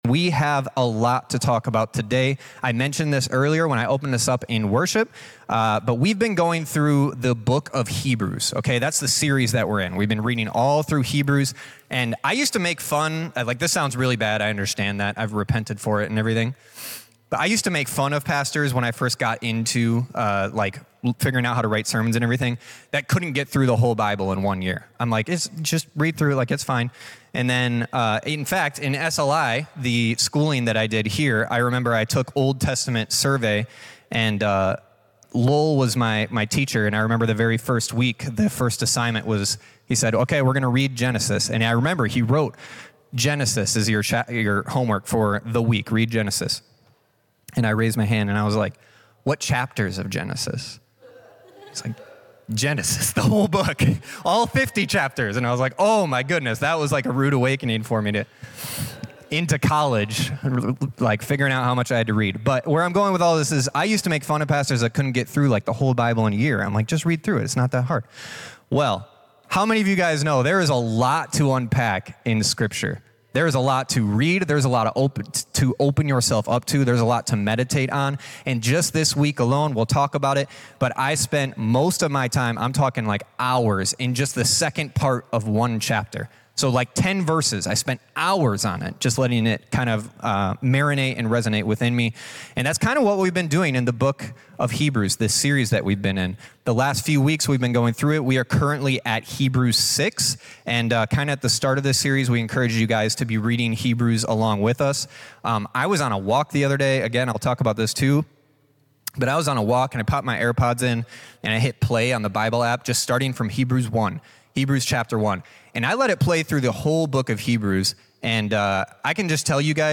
This sermon delves into the biblical promise of entering God's rest, drawn from Psalm 95 and Hebrews 3. Rest is not simply about physical peace, but a deep, inner spiritual rest that comes from trusting in Jesus.